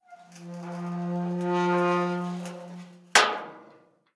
Abrir una puerta de metal grande
Sonidos: Acciones humanas
Sonidos: Hogar